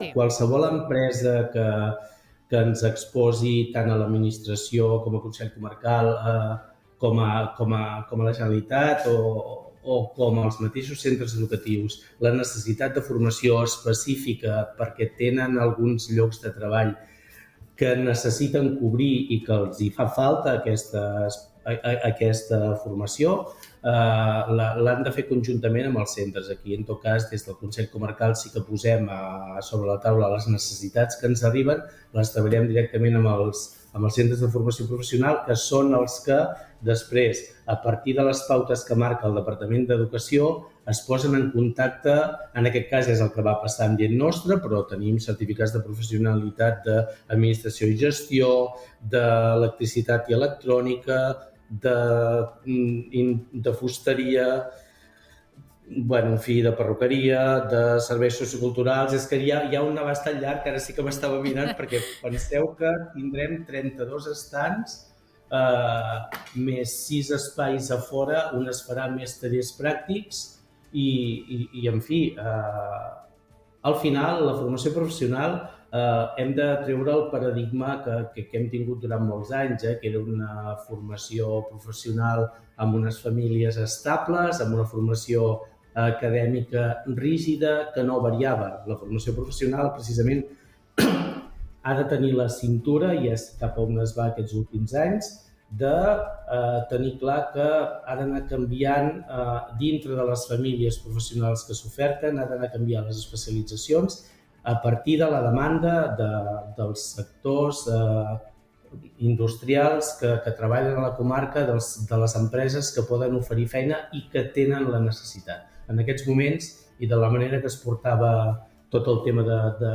Per això ens ha visitat el vicepresident primer del Consell Comarcal del Baix Empordà, Enric Marquès, al Supermatí.